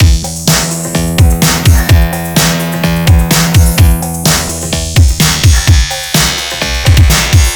127 Some Strange Flange.wav